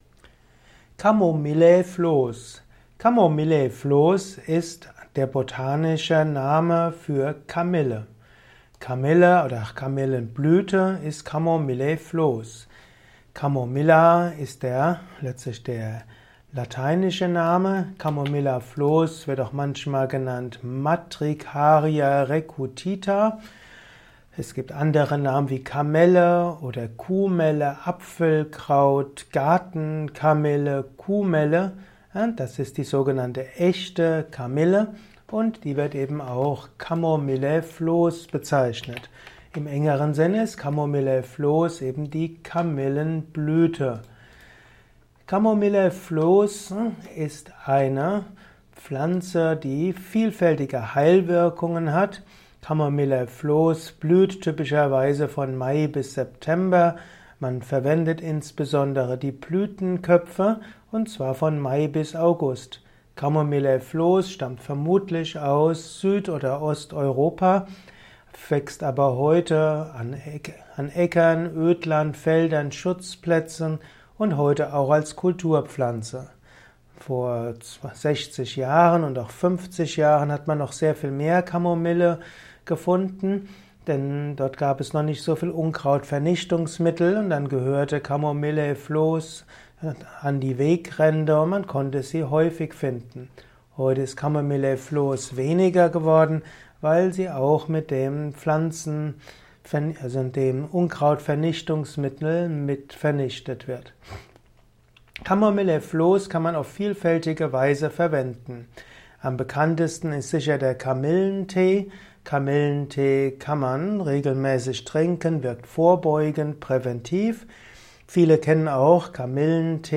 Erfahre Interessantes über die Pflanze Chamomillea flos (Kamille) in diesem Kurzvortrag